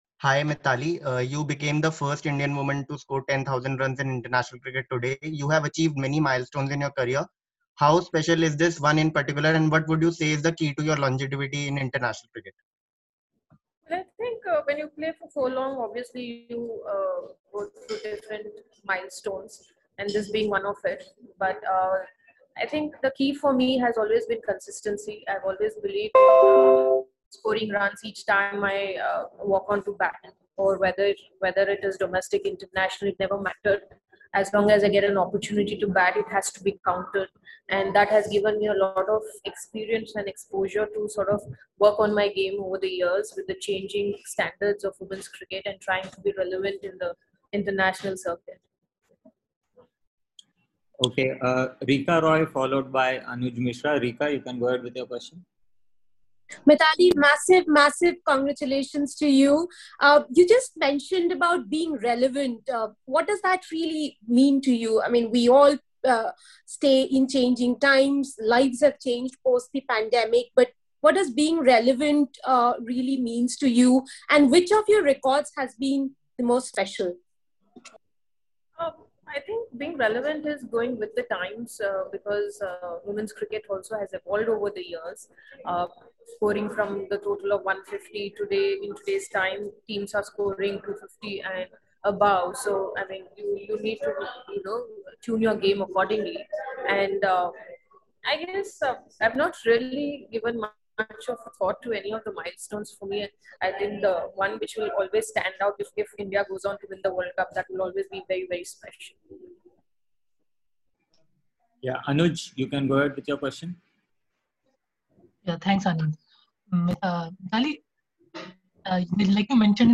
Mithali Raj, Captain, Indian Women’s ODI Team addressed a virtual press conference after the 3rd ODI against South Africa at Lucknow.